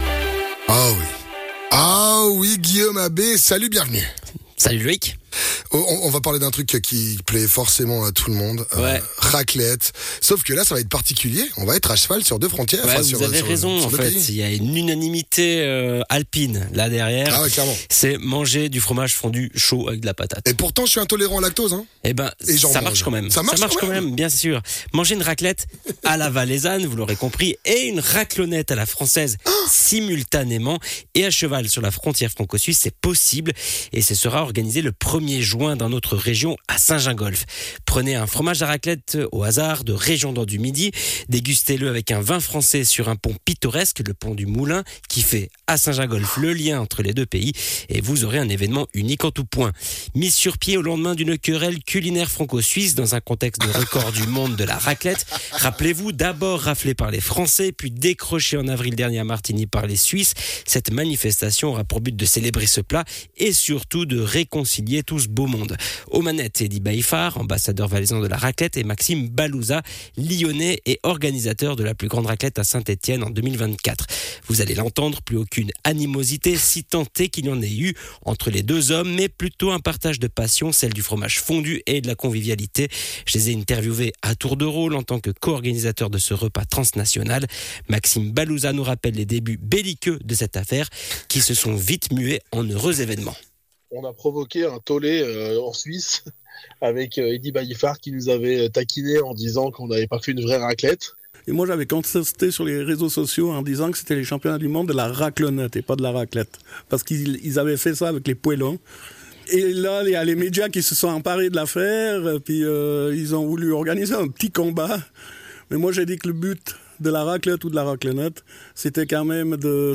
Intervenant(e) : Les co-organisateurs valaisanno-lyonnais